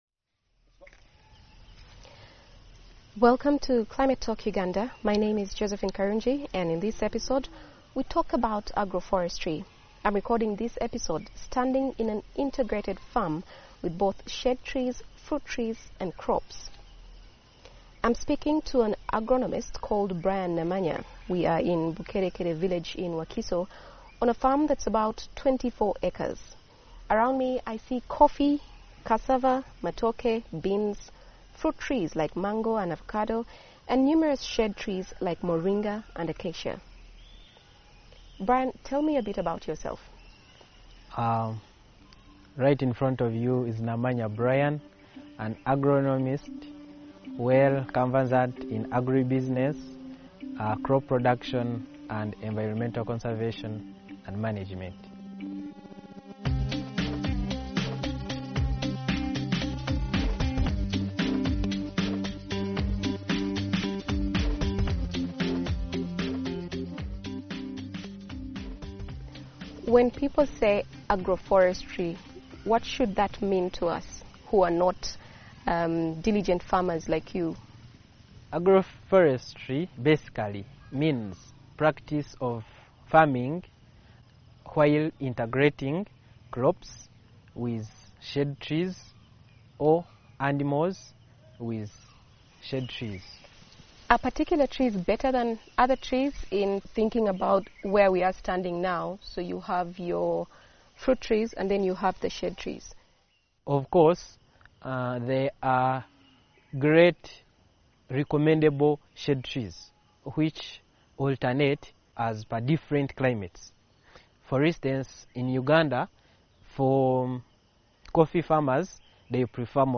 In this episode we hear from two locations: Bukerekere Village in Central Uganda and Rhino Camp Refugee Settement in the Arua District, in the north west of the country. From these geographically distinct areas we hear first-hand how agroforestry works, and why it's one way communities can practice sustainable and profitable agriculture in a changing climate.